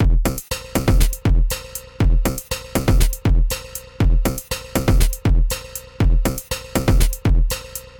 描述：炉石传说被击败时的音效
Tag: 炉石传说 游戏音效 视频配音